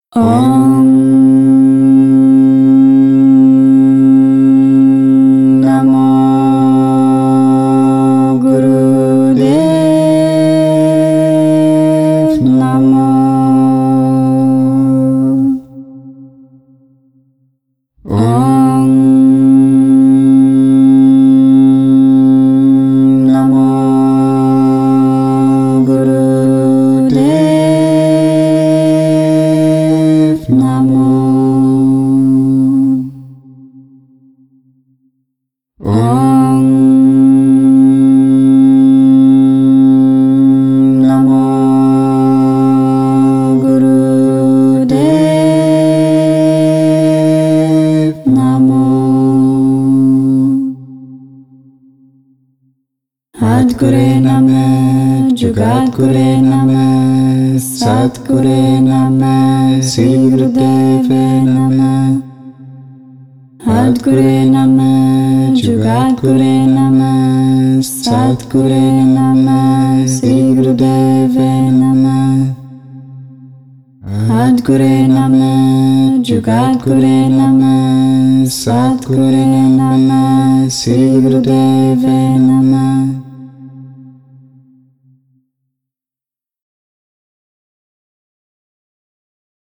Sissehäälestus enne joogapraktikat